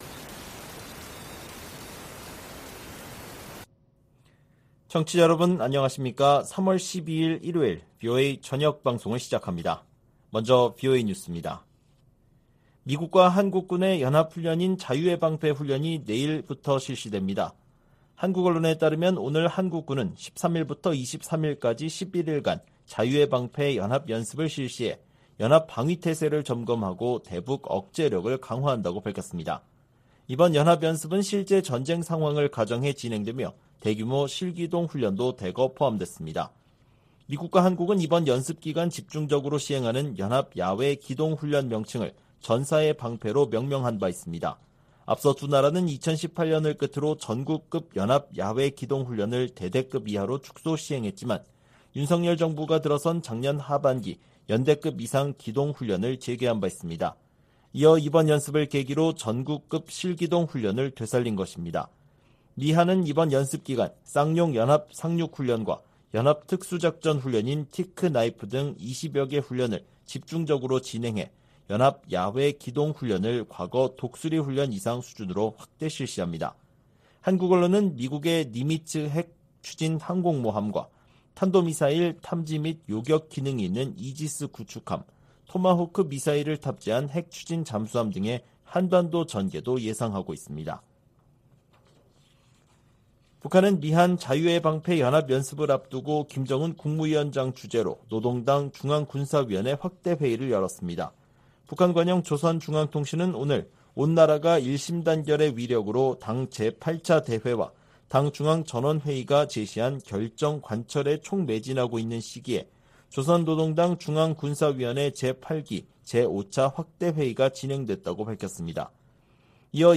VOA 한국어 방송의 일요일 오후 프로그램 1부입니다.